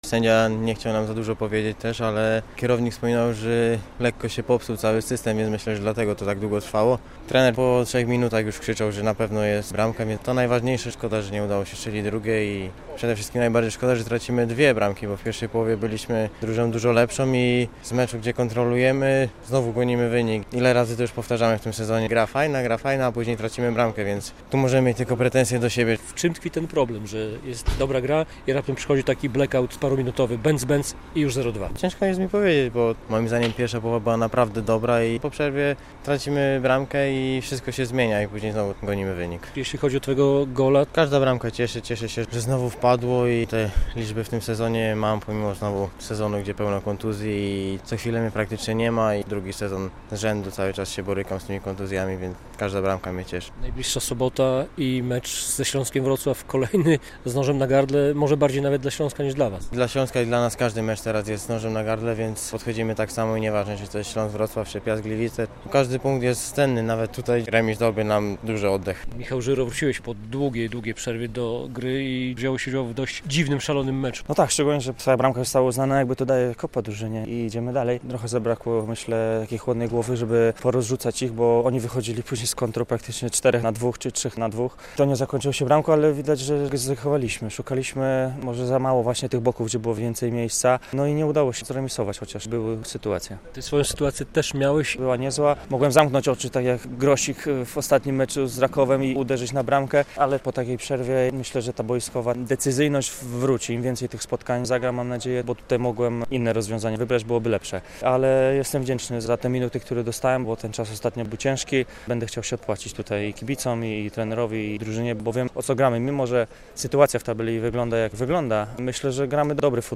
Z piłkarzami Jagiellonii rozmawia